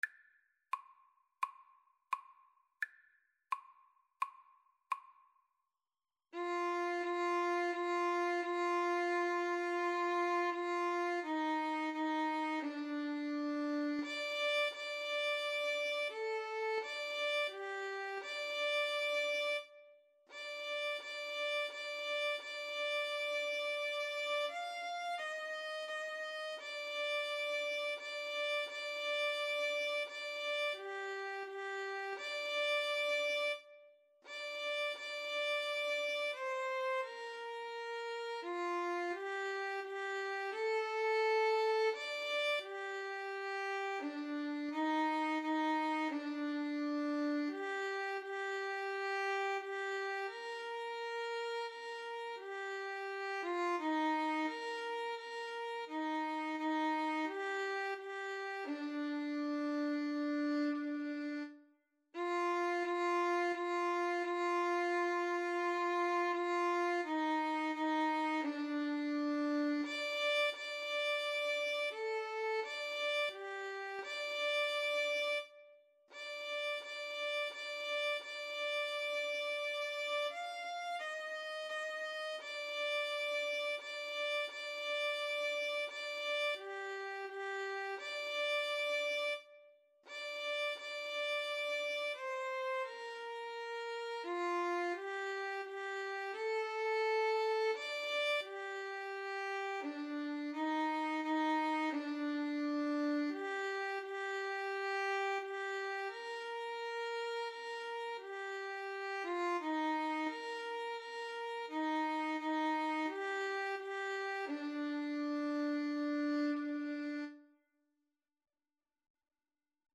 Andante =c.86